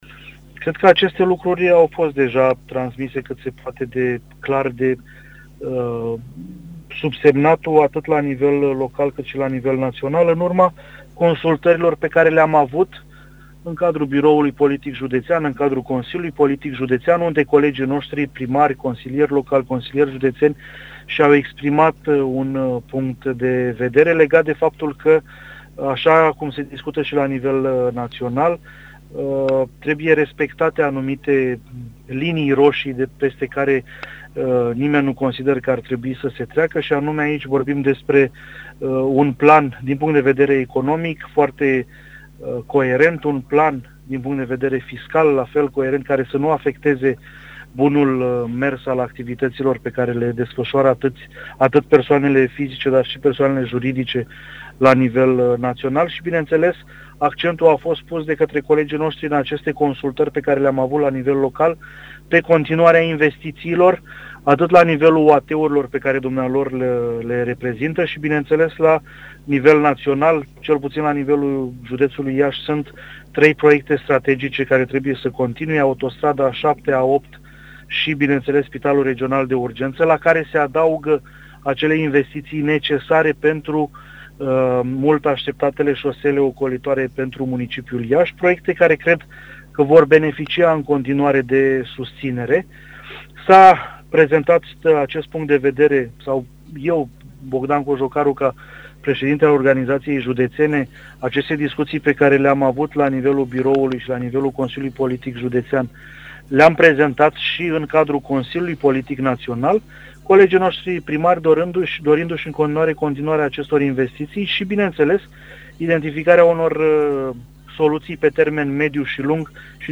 Interviu-Bogdan-Cojocaru-doi.mp3